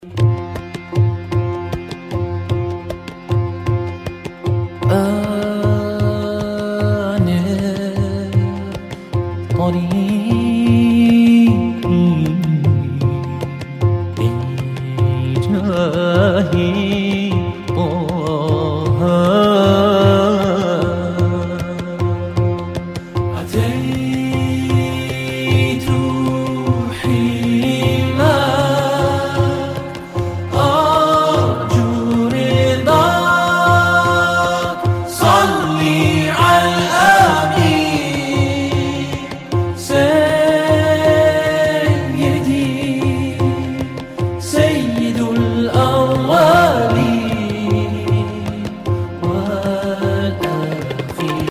رینگتون با کلام ملایم و زیبای
با ملودی پاکستانی